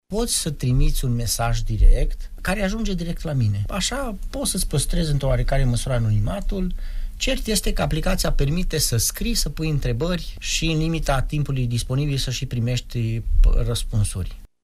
a declarat în emisiunea Pulsul Zilei de la RTM